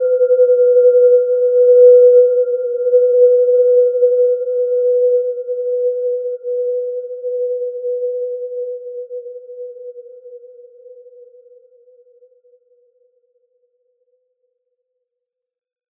Gentle-Metallic-3-B4-p.wav